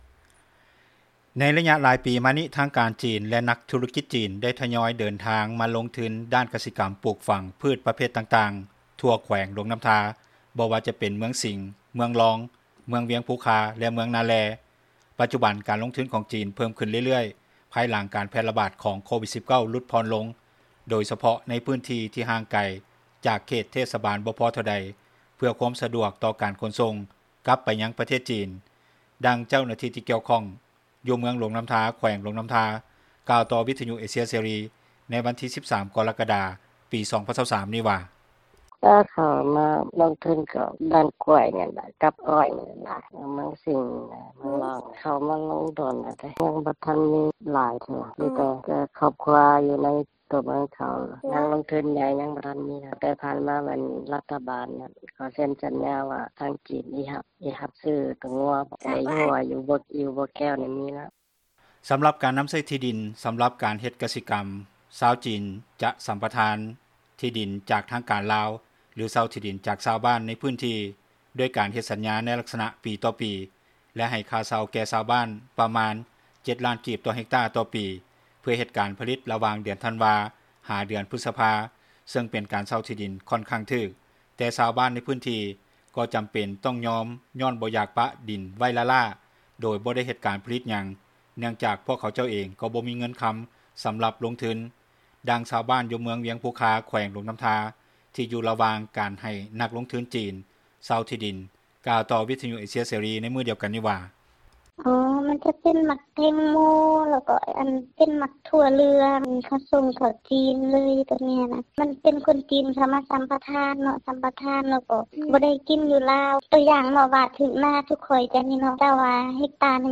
ດັ່ງຊາວບ້ານ ຢູ່ເມືອງວຽງພູຄາ ແຂວງຫຼວງນໍ້າທາ ທີ່ຢູ່ລະຫວ່າງການໃຫ້ນັກລົງທຶນຈີນ ເຊົ່າທີ່ດິນກ່າວຕໍ່ວິທຍຸ ເອເຊັຽ ເສຣີ ໃນມື້ດຽວກັນນີ້ວ່າ:
ດັ່ງຄົນງານລາວ ທີ່ເຮັດວຽກຢູ່ສວນໝາກໂມ ຂອງຈີນ ຢູ່ເມືອງວຽງພູຄາ ແຂວງຫຼວງນໍ້າທາ ນາງນຶ່ງກ່າວວ່າ: